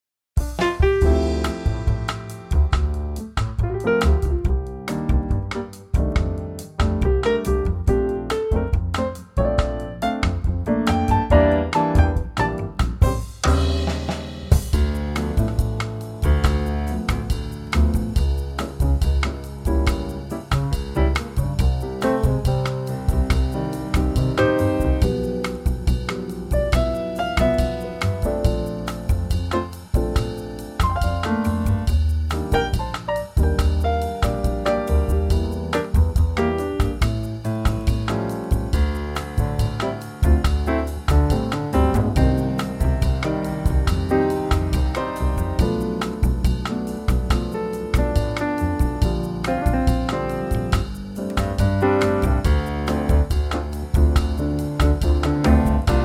key - Dm - vocal range - D to C
slightly brighter in tempo.
backing track